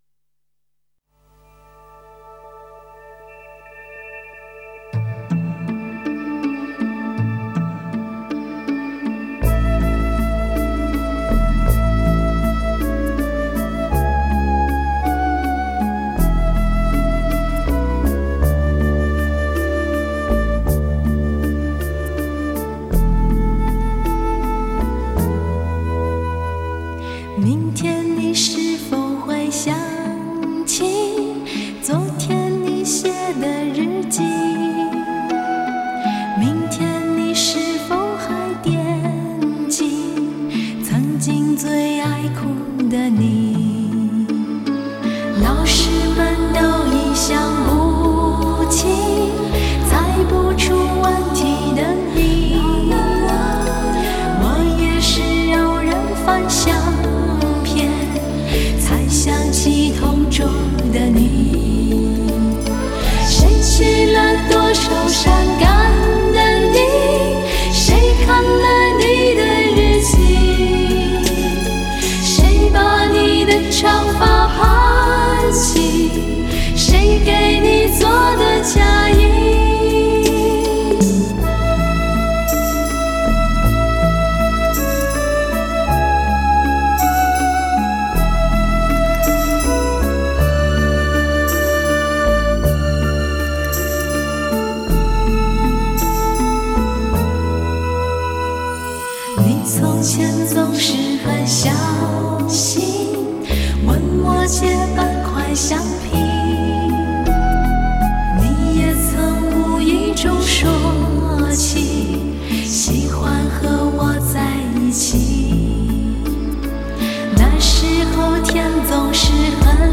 如何寻找一份寂静与解脱……清纯、甜美的歌声，